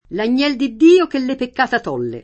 l an’n’$l di dd&o ke lle pekk#ta t0lle] (Dante) — cfr. cogliere; sciogliere; to’